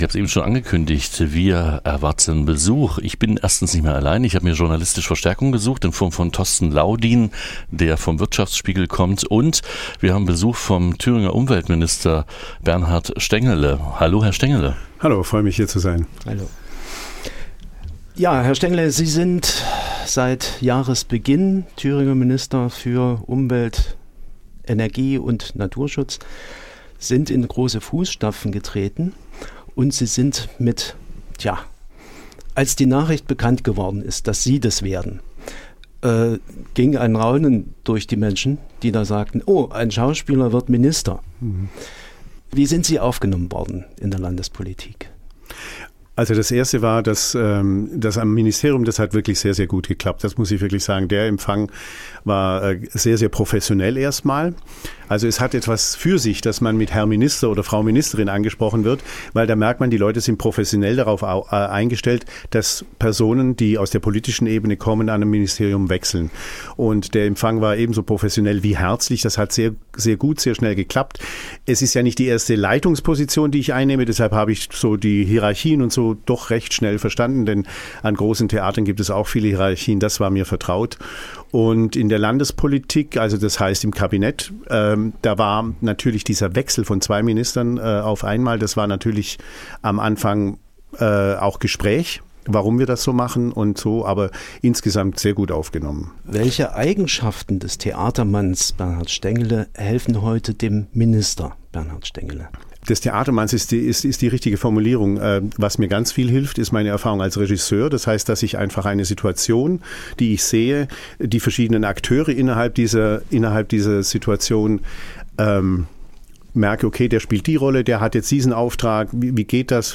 Die Kunst des Schauspiels in der Politik - ein Gespräch mit Thüringens Umweltminister Bernhard Stengele
Interview Bernhard Stengele.mp3